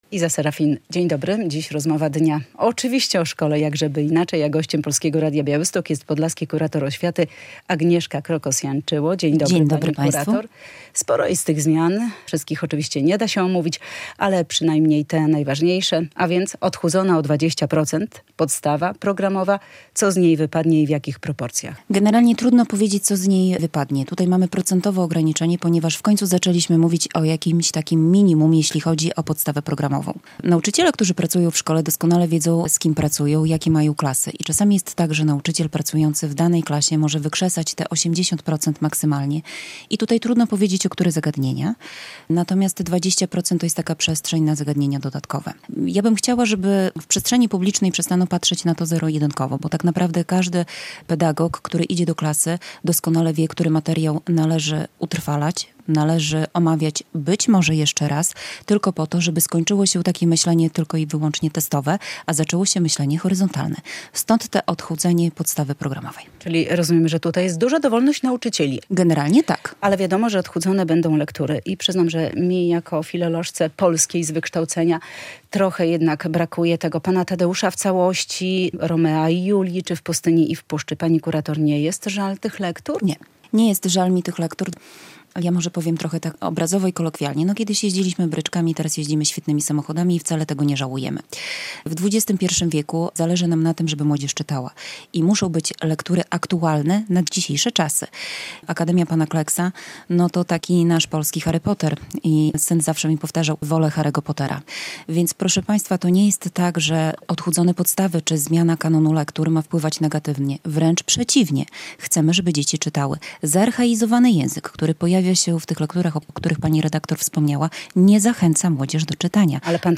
podlaska kurator oświaty